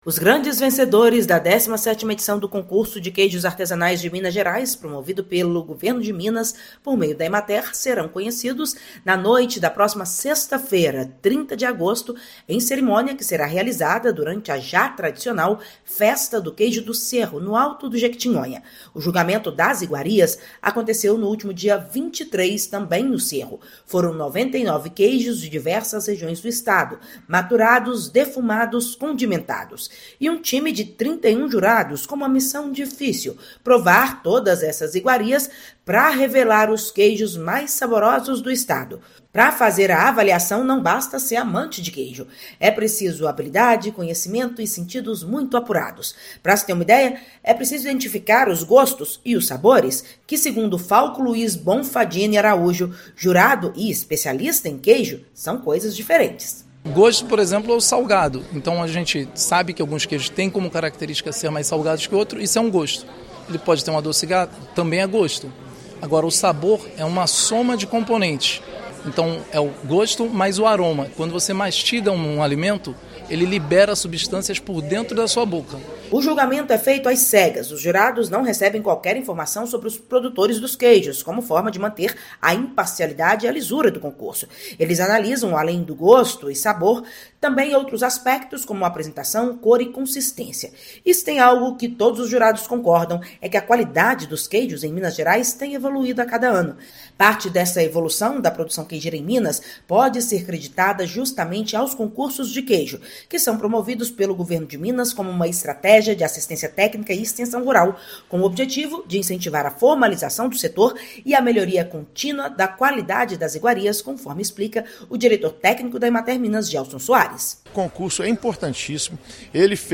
Serão cinco vencedores em cada uma das quatro categorias do concurso e a premiação será nesta sexta-feira (30/8). Ouça matéria de rádio.